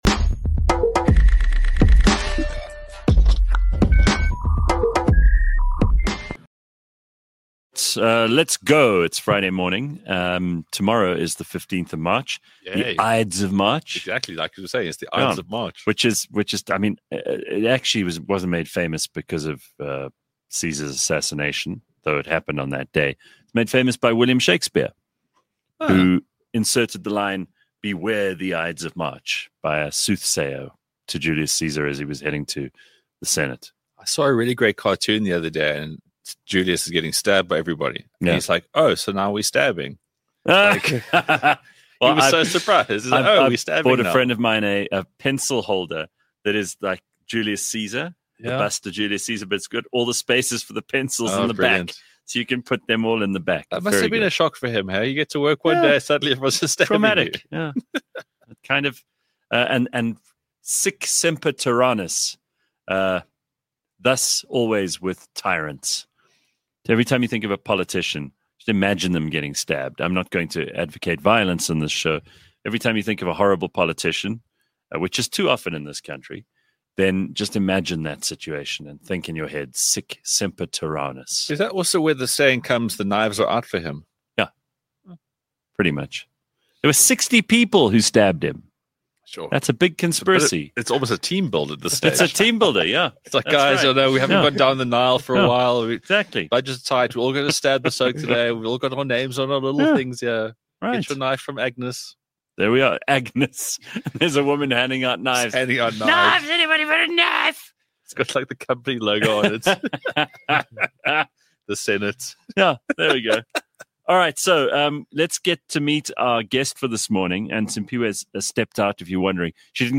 A live podcast show, that’s like a morning radio show, just much better. Live from 6h00-8h00 Monday, Wednesday and Friday. Clever, funny, outrageous and sometimes very silly.